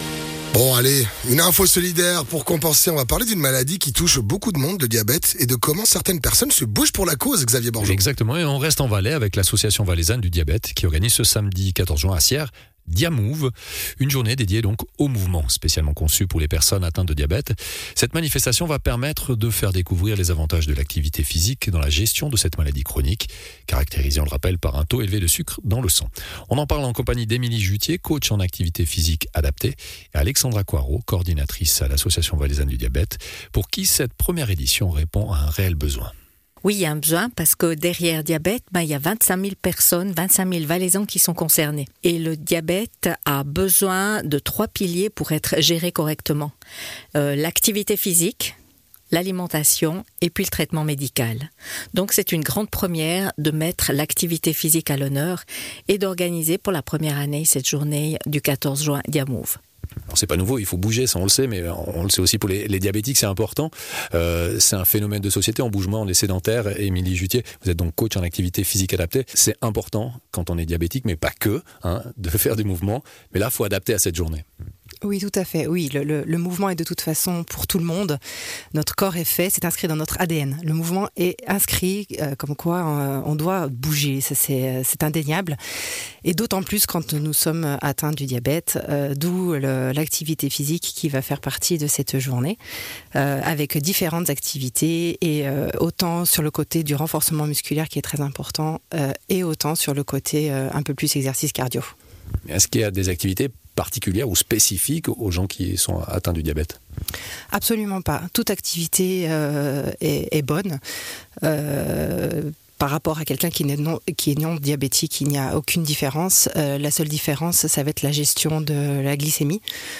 coach en activité physique adaptée